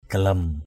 /ɡ͡ɣa-lʌm/ (cv.) gilem g{l# (đg.) vác = porter sur l’épaule. carry on shoulder. galem jaléng nao hamu gl# j_l@$ _n< hm~% vác cuốc đi ruộng. galem kayau trun cek gl#...